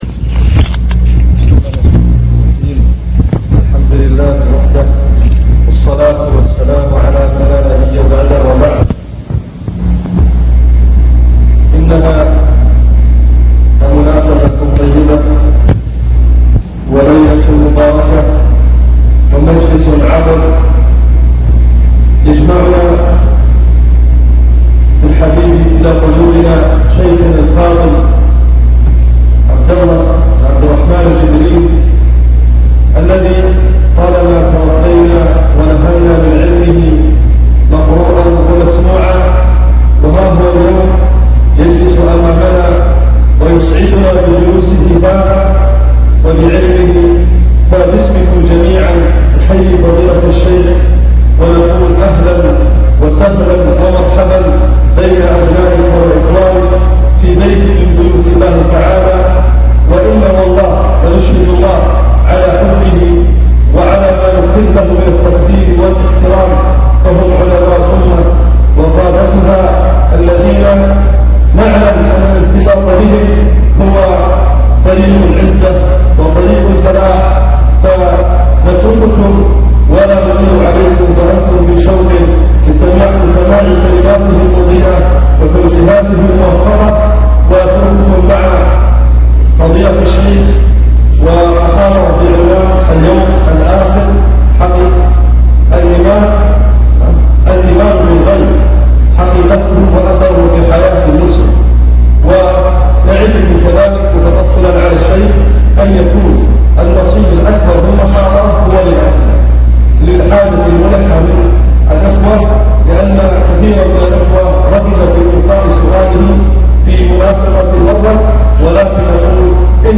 المكتبة الصوتية  تسجيلات - محاضرات ودروس  محاضرة الإيمان باليوم الآخر
تقديم